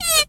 pgs/Assets/Audio/Animal_Impersonations/mouse_emote_06.wav at master
mouse_emote_06.wav